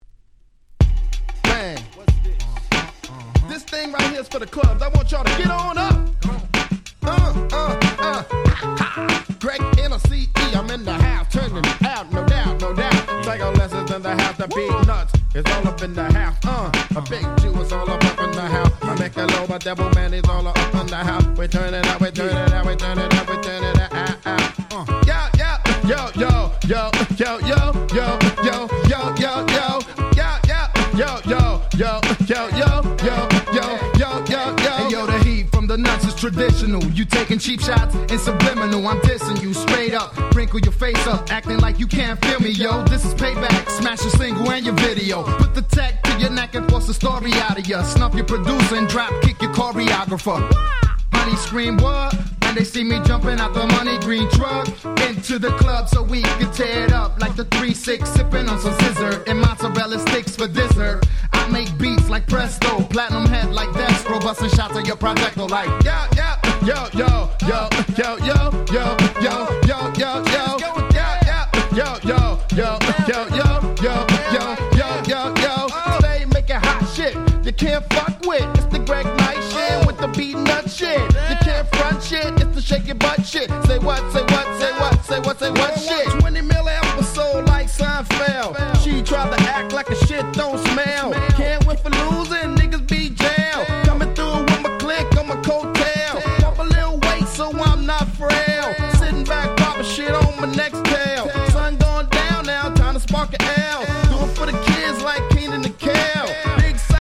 01' Smash Hit Hip Hop !!